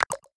closeMenu.ogg